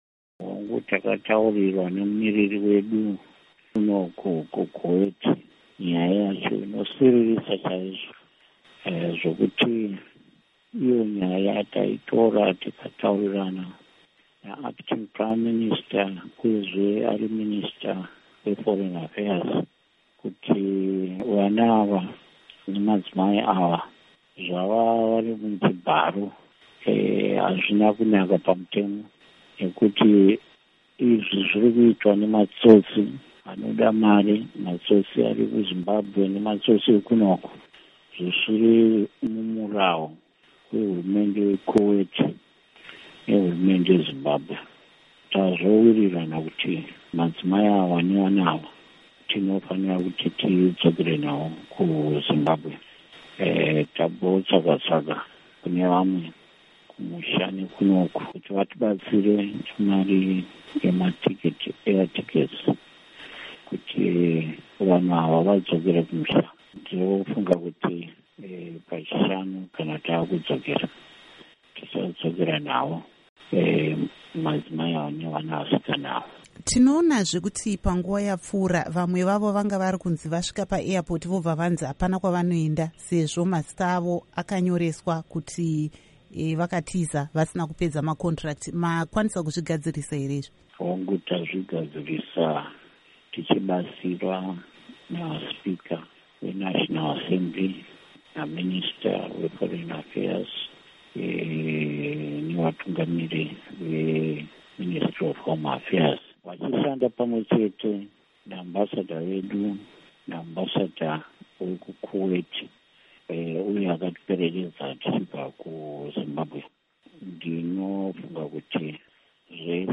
Hurukuro NaVa Jacob Mudenda